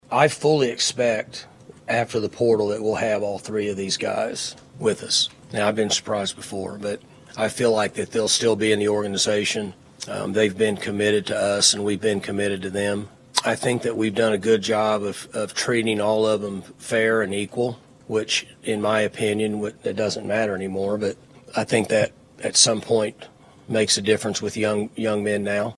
Oklahoma State head football coach Mike Gundy had his final weekly press conference of the season on Monday, as the Cowboys wrap up their 2024 season at Colorado on Friday morning.